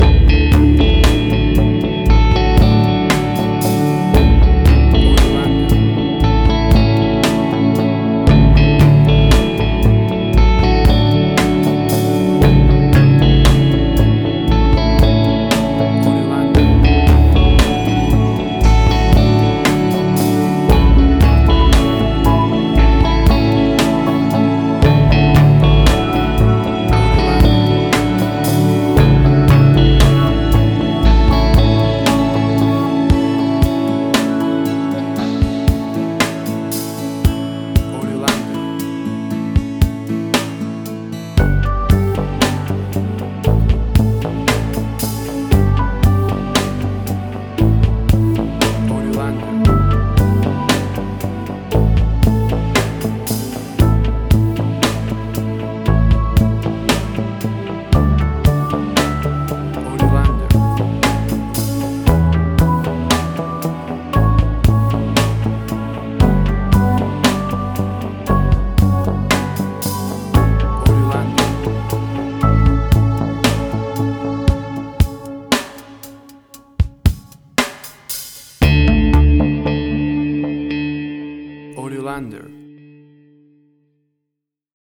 Tempo (BPM): 58